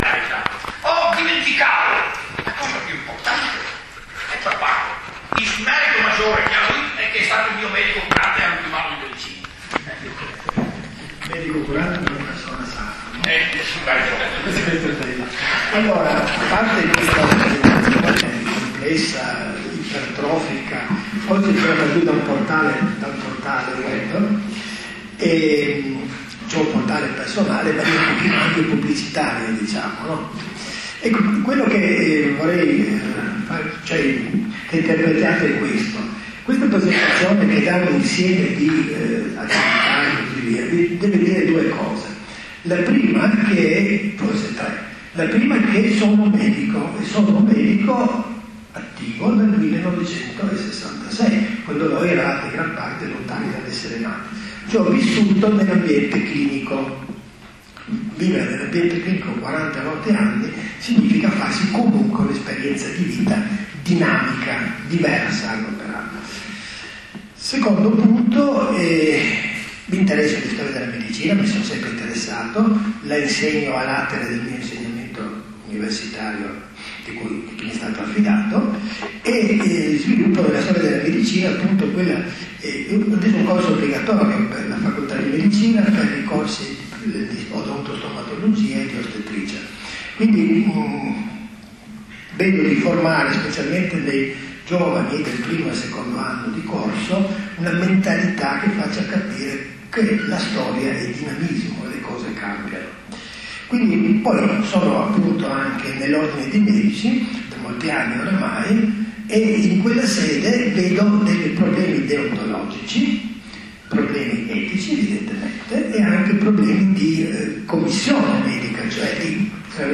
File audio conferenza Etica ed Economia nella Sanit�